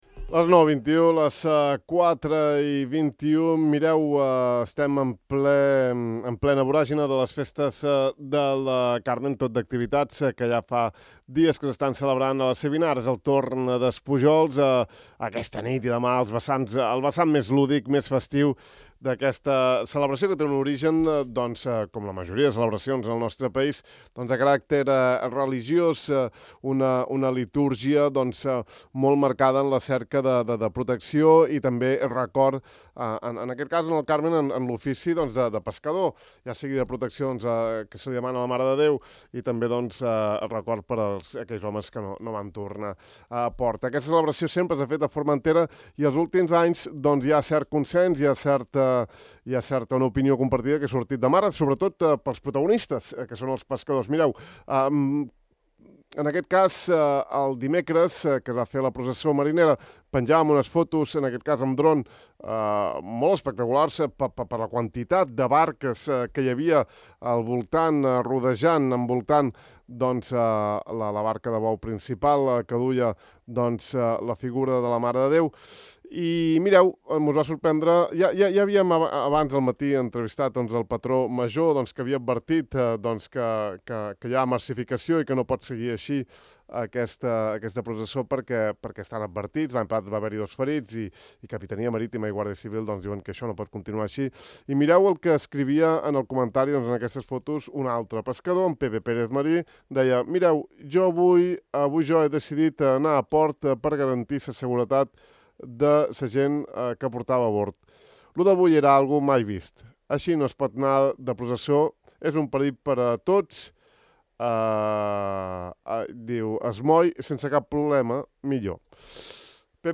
Avui hi hem xerrat: